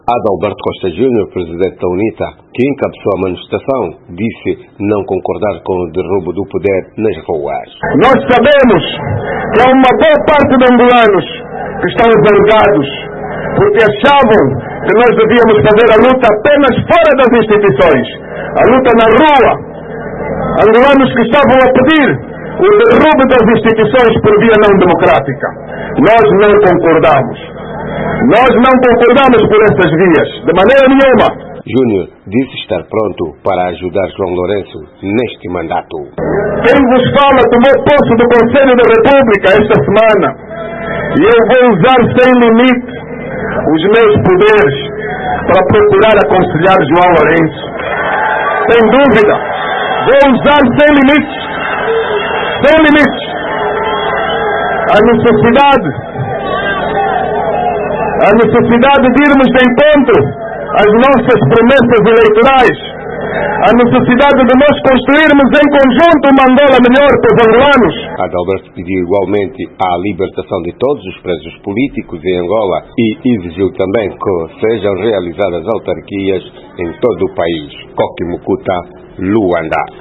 Luanda - Membros e simpatizantes da UNITA e da Frente Patriota marcharam, este sábado (24), pela "liberdade, justiça, democracia e direitos humanos”, ocasião que o líder do partido, Adalberto Costa Júnior (ACJ), usou para dizer que “o preço do poder não vale o sangue do meu povo nas ruas”.
No seu discurso, ACJ exigiu reformas profundas na Comissão Nacional Eleitoral, Tribunal Constitucional, Entidade Reguladora da Comunicação Social (ERCA) e meios de comunicação social públicos.